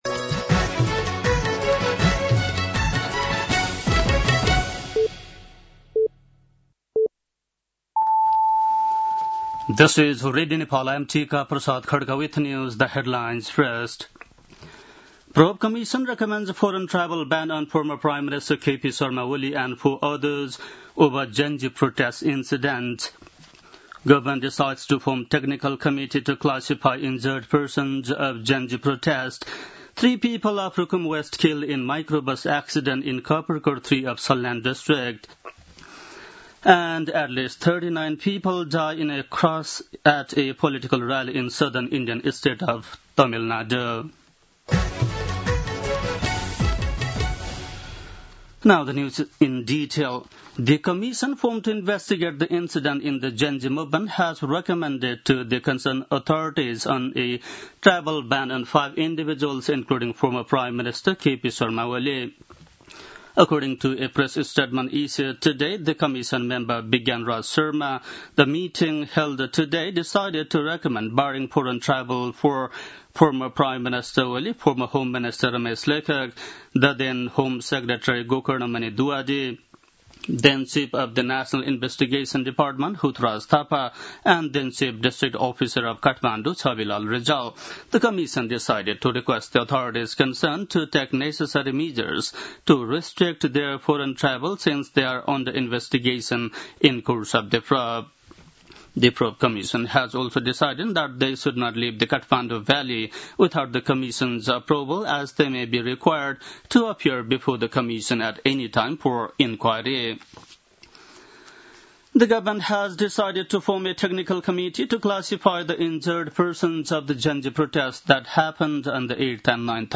बेलुकी ८ बजेको अङ्ग्रेजी समाचार : १२ असोज , २०८२
8-pm-english-news-6-12.mp3